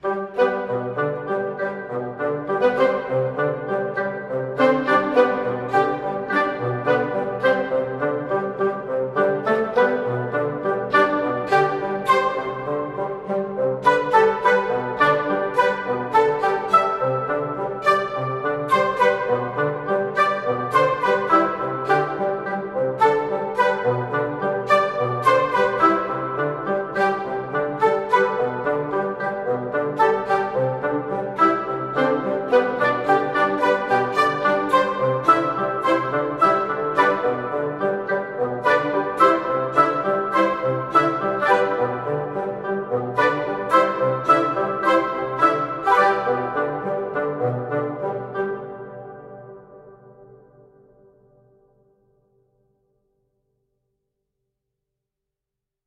Instrumental Version